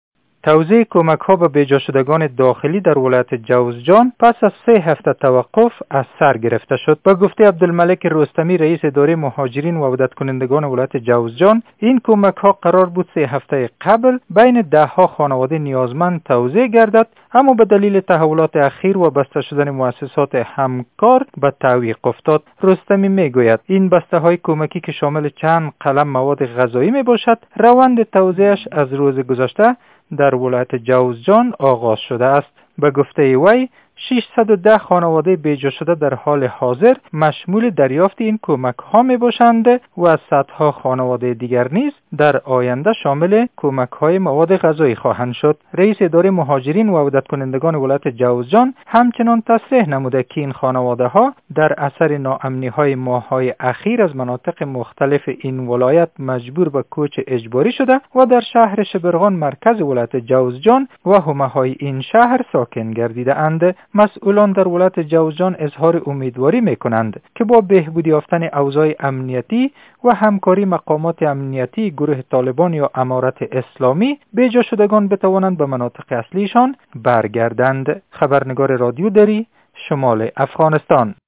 گزارش تکمیلی از خبرنگار رادیو دری